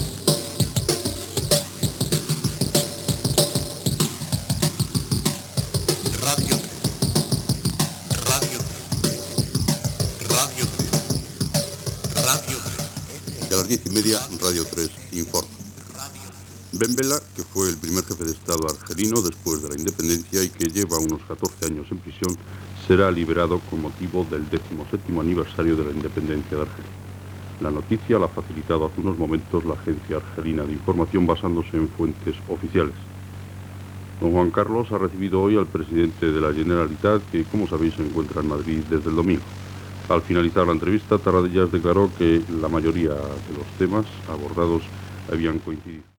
ed47d5a7da2700f107bf3f7160ac96ff0aa8313e.mp3 Títol Radio 3 Emissora Radio 3 Cadena RNE Titularitat Pública estatal Nom programa Radio 3 Informa Descripció Alliberament de Bembela, el president Tarradellas visita el rei Juan Carlos. Gènere radiofònic Informatiu